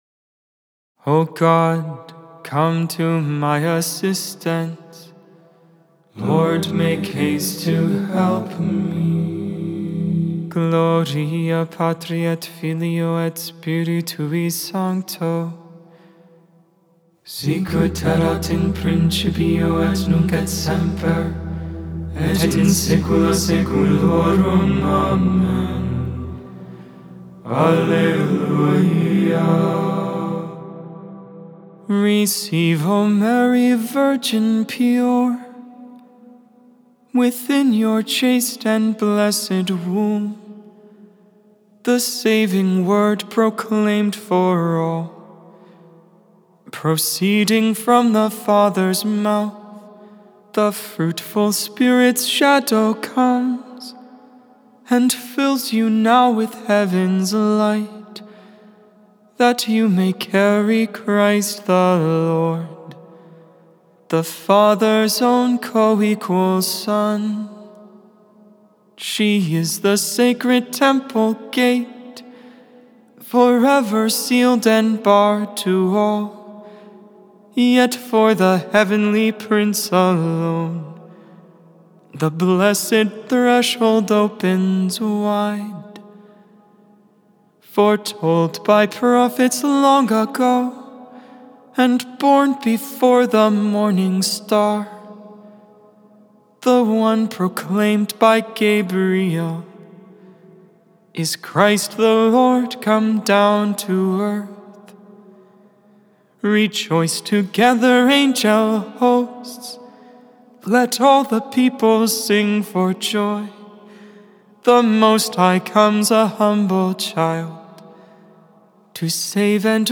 12.18.24 Vespers, Wednesday Evening Prayer